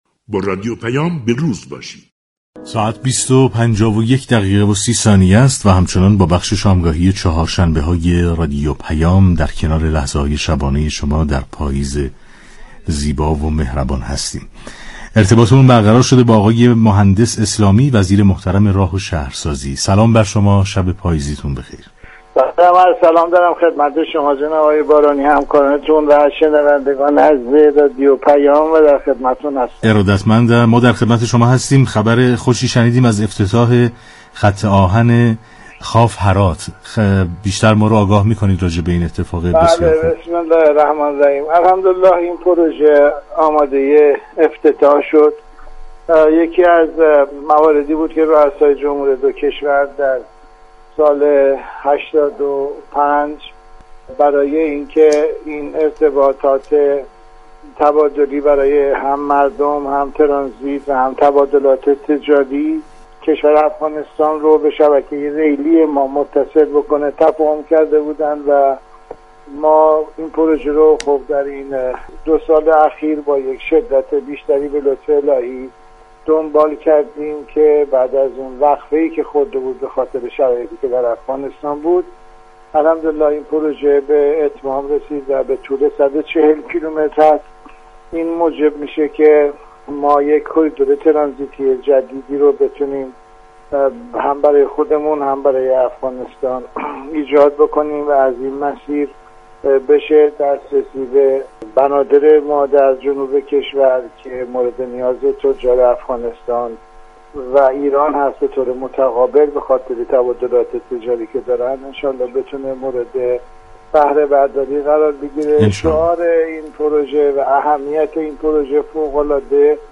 مهندس اسلامی ، وزیر راه و شهرسازی در گفتگو با رادیو پیام ، جزئیاتی از احداث راه آهن خواف- هرات كه فردا با حضور روسای جمهور دو كشور ایران و افغانستان افتتاح خواهد شد را بازگو كرد .